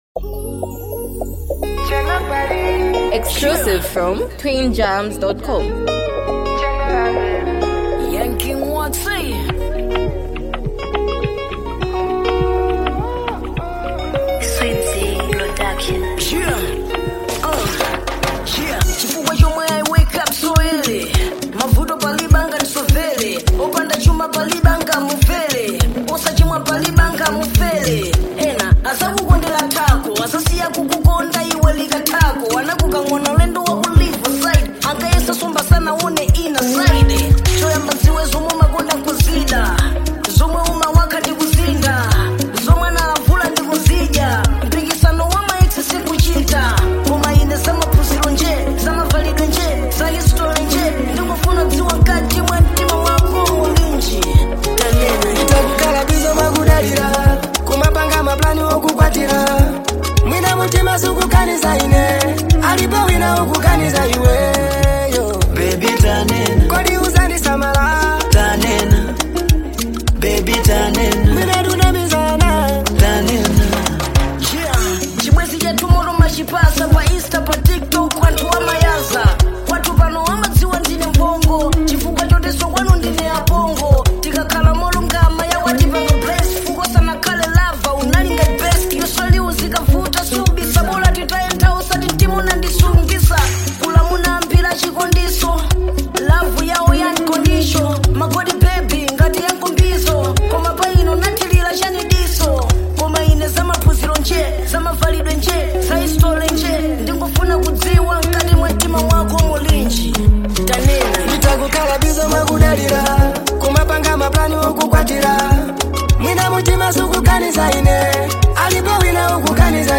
The song carries a sincere tone
love song